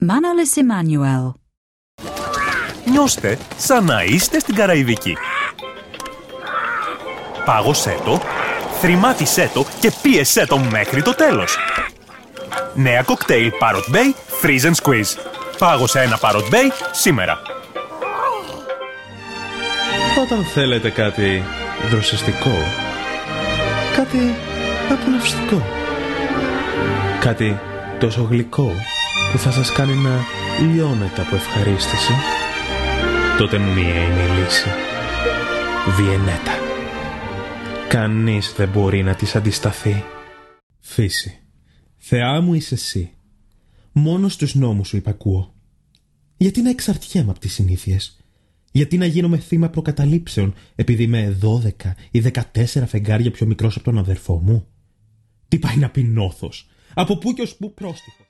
Greek voiceover artist